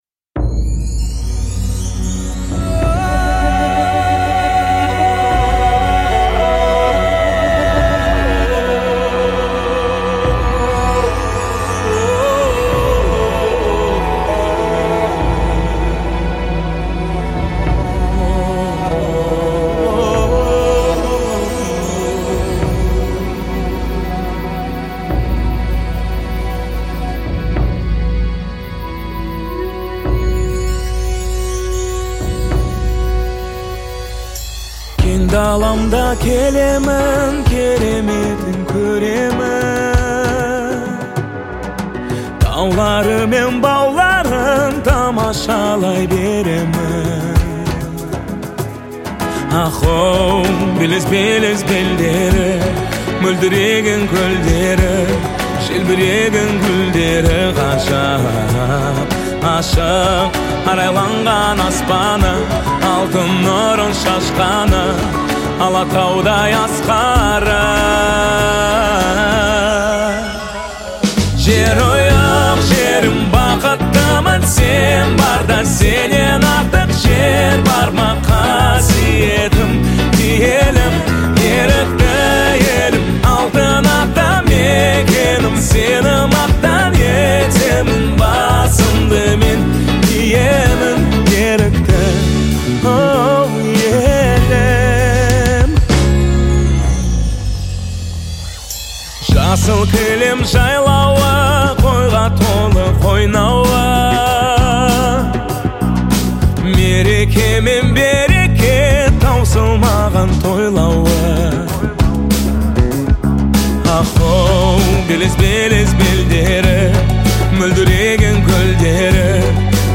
это трек в жанре казахского попа